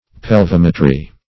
Pelvimetry \Pel*vim"e*try\, n. [Pelvis + -metry.] (Med.)